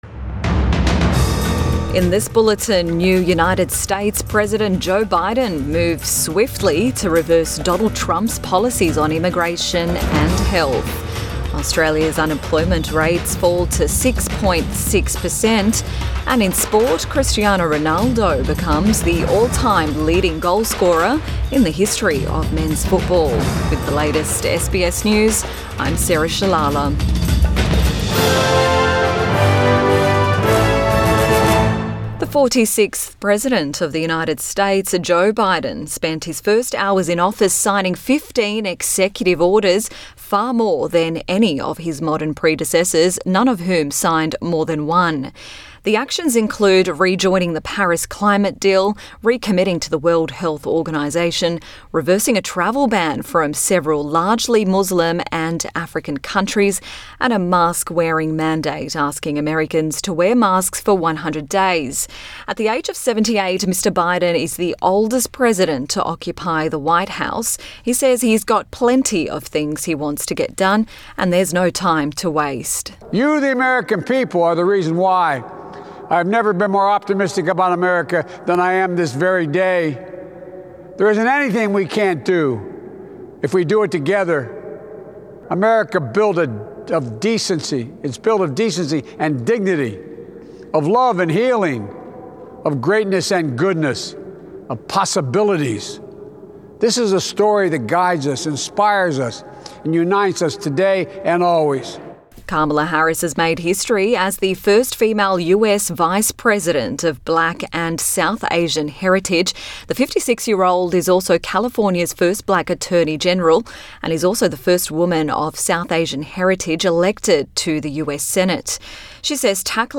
PM bulletin 21 January 2021